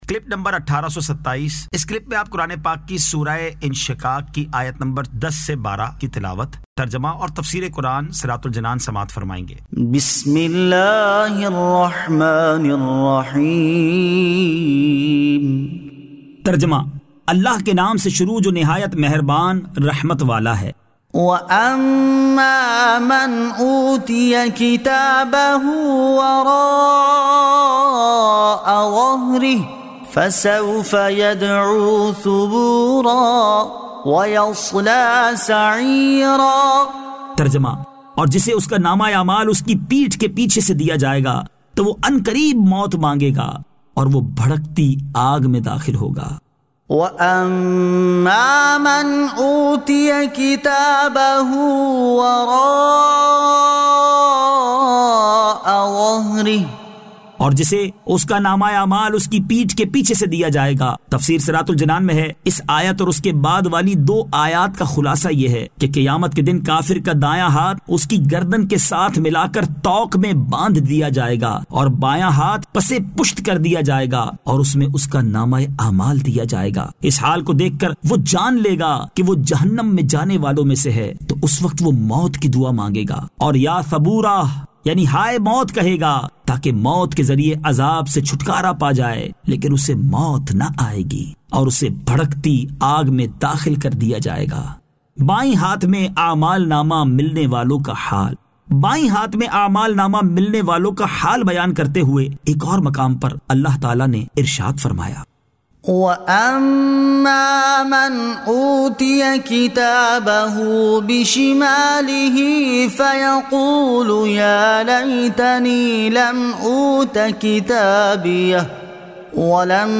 Surah Al-Inshiqaq 10 To 12 Tilawat , Tarjama , Tafseer